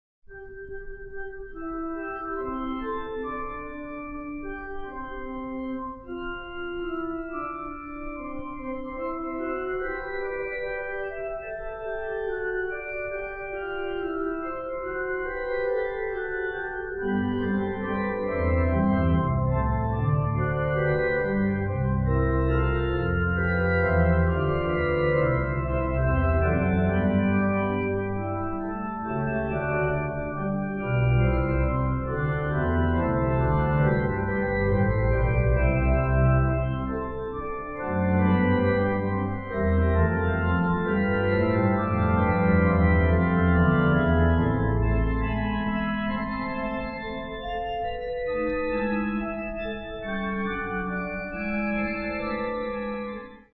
Later works fully in the style of Vienna Classicism.
Works: more than 100 symphonies, about 100 string quartets, many piano sonatas, sonatines etc., about 40 organ fugues, cathedral compositions and theoretical works